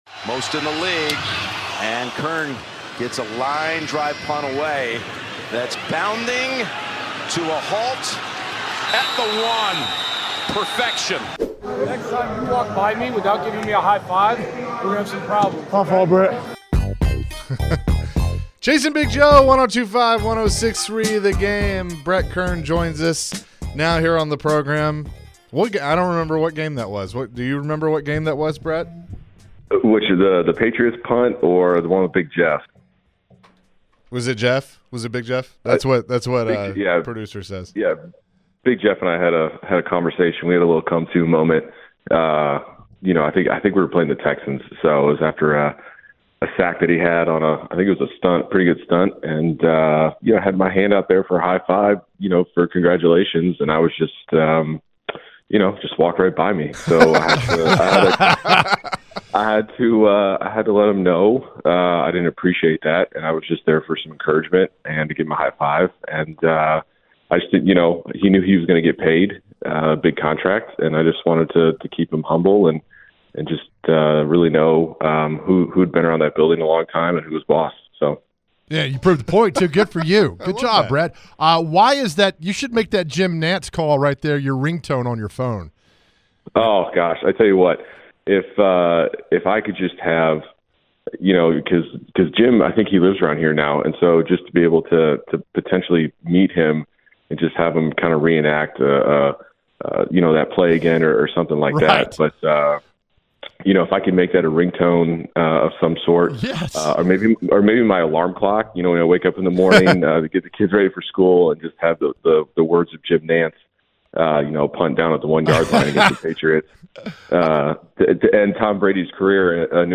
Former Titans punter Brett Kern joined the show and discussed his thoughts about the new hires on the Titans staff with Brian Callahan. Later in the hour, Brett was asked about advice he would give to new head coach Brian Callahan on hiring the next special teams coordinator.